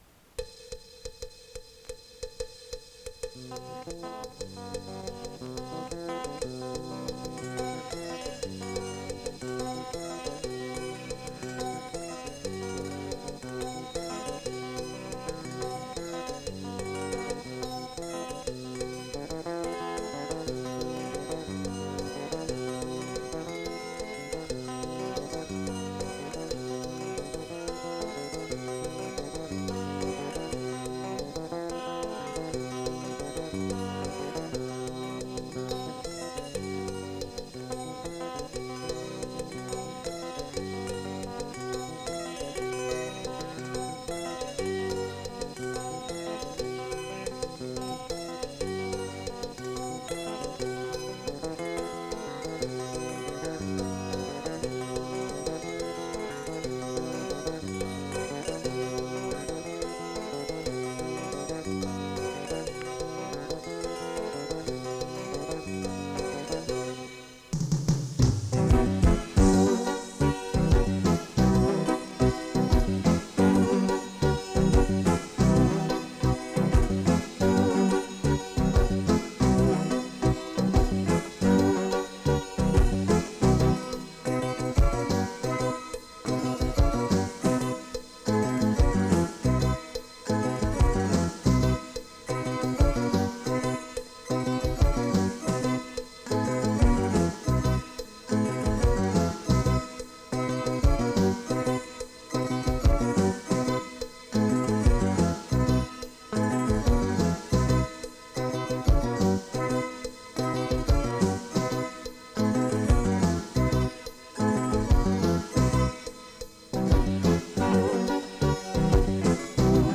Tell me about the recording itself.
I played/sequenced the other parts pretty much as directed by him. It was recorded using Performer for Mac, and a TEAC 2340 4-track 1/4" 7.5IPS reel-to-reel, synced to the Mac via SMPTE (from a Jambox)